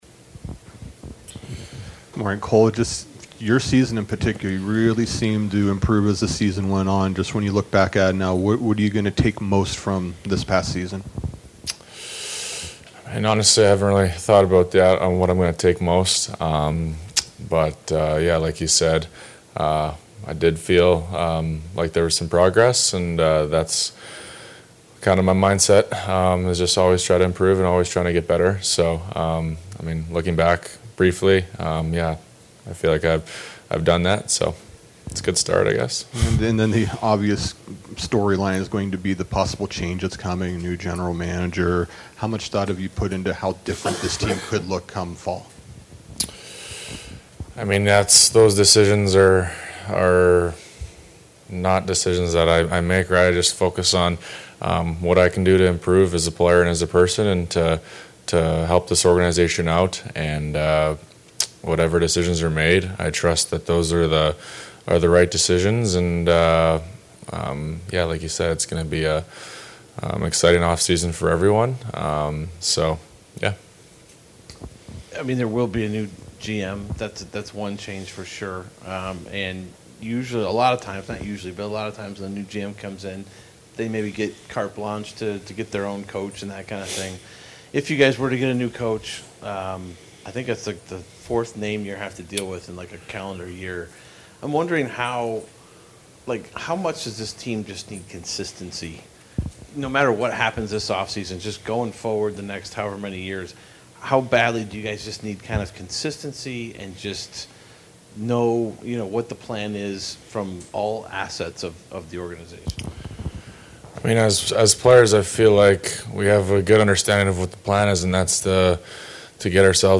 Blue Jackets center Cole Sillinger in 2023-24 Season-Ending Exit Interviews; says he and teammates appreciate 16-18,000 fans at home games despite tough times